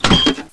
SOUNDS: Add vending sound
vend.wav